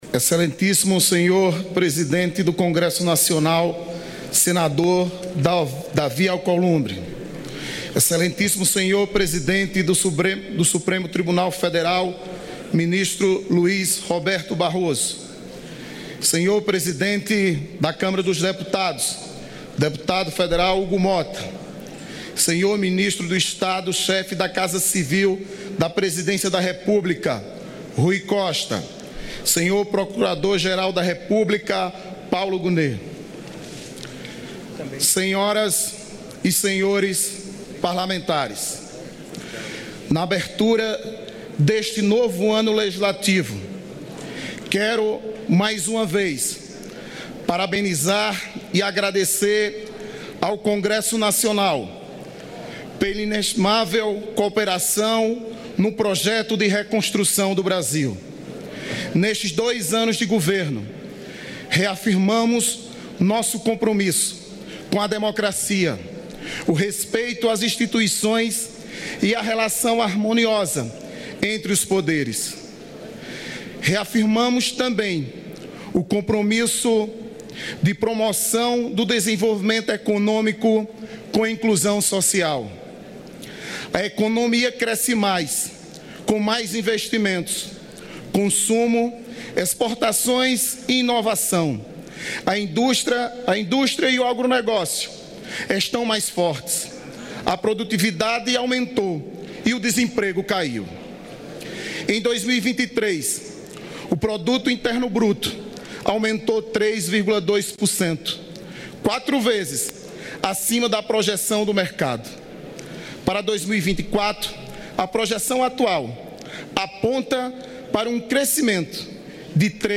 Abertura do Ano Legislativo Mensagem do Presidente da República A mensagem presidencial foi lida pelo primeiro-secretário da Câmara, deputado Carlos Veras (PT-PE), após sua entrega formal pelo ministro da Casa Civil, Rui Costa. O texto apresenta um balanço dos primeiros anos do mandato do presidente Lula.